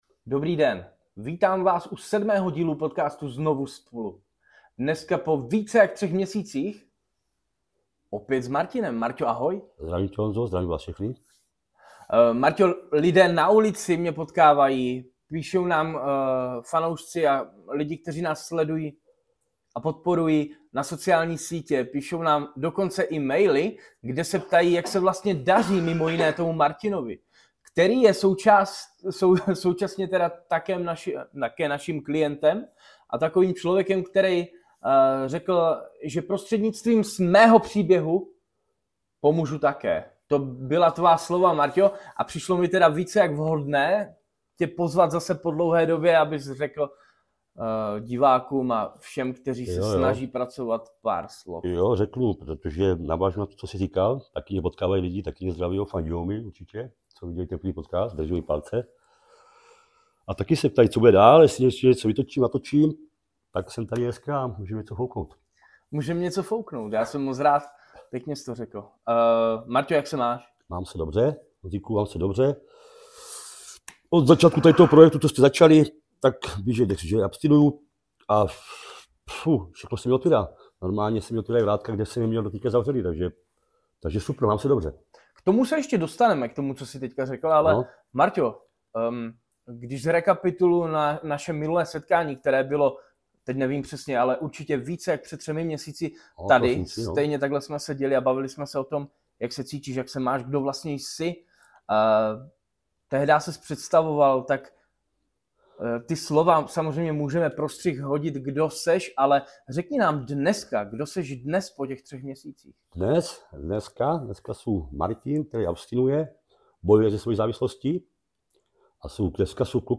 rozhovor dvou kamarádů - ZNOVU SPOLU